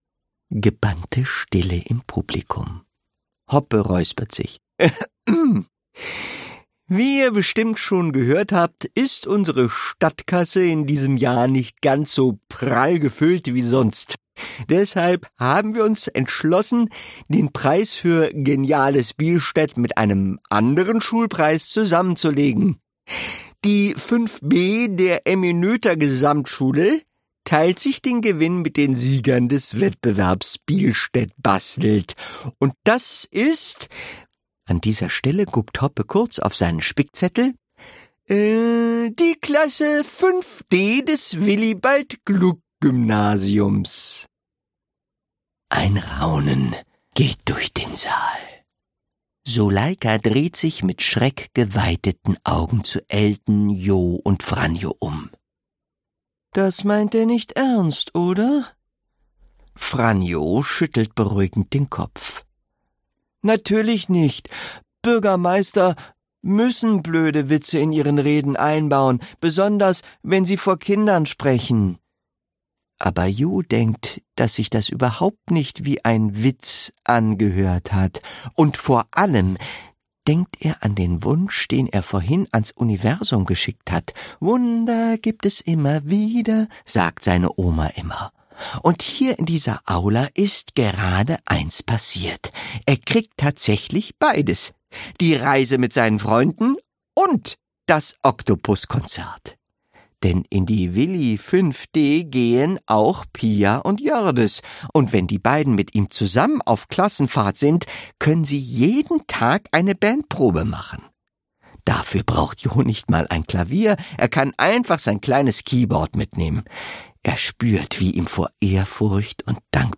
Gekürzte Lesung